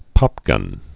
(pŏpgŭn)